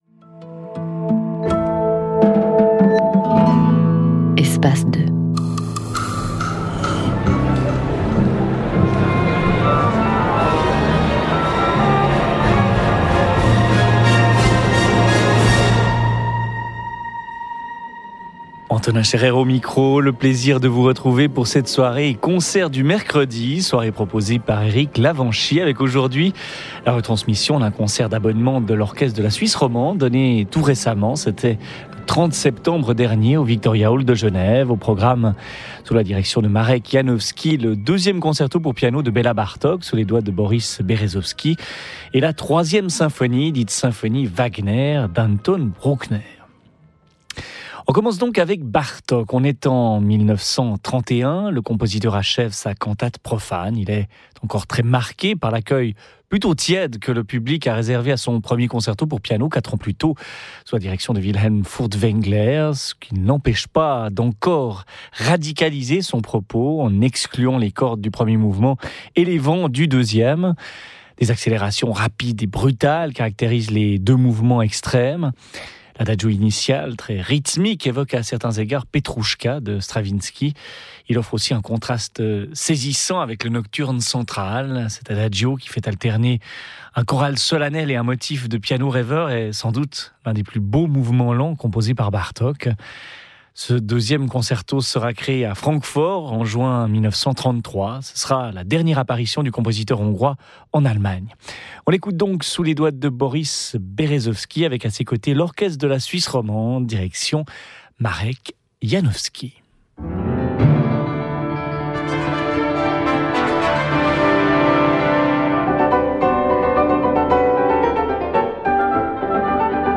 Boris Berezovsky, Piano – Orchestre de la Suisse Romande, Marek Janowski, cond.
Over to Geneva this week for a concert by the celebrated Orchestre de la Suisse Romande lead by Marek Janowski and featuring Boris Berezovksy, piano in performances of works by Bartok and Bruckner. Recorded live on September 30, 2011 by Radio Suisse Romande Espace 2.
The concert begins with a Bartok’s Piano Concerto Number 2 (with encore), followed by the 3rd Symphony of Anton Bruckner.